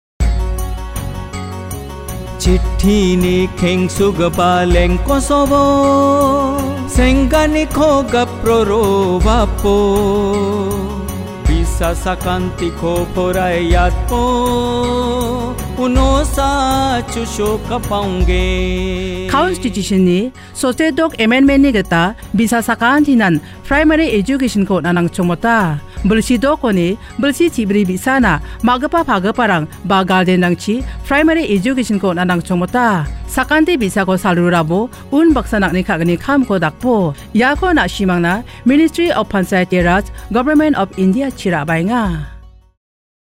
57 Fundamental Duty 11th Fundamental Duty Duty for all parents and guardians to send their children in the age group of 6-14 years to school Radio Jingle Garo